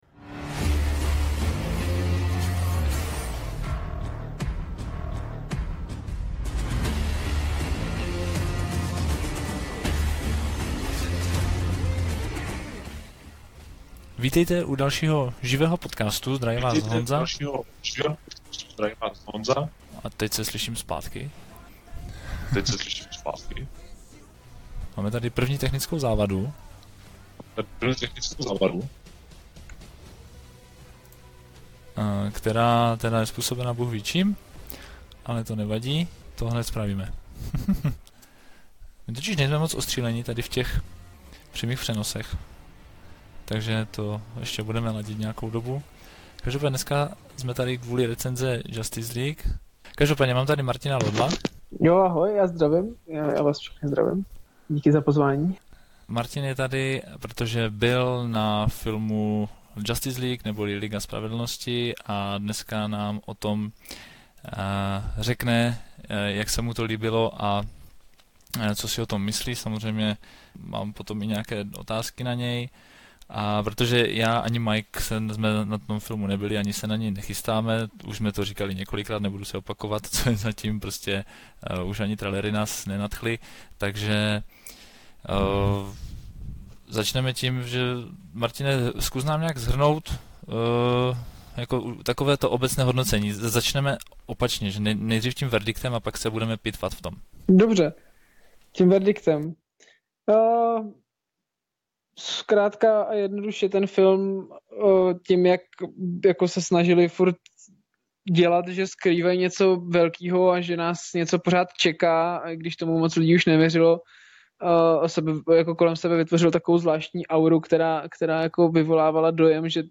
Celé jsme to vysílali živě na facebooku , kde se nám nevyhnuly trochu technické potíže, takže je to místy trochu znát a za to se omlouváme. Záznam nabízíme v původní verzi na YouTube a potom jako lehce učesanou mp3 verzi níže.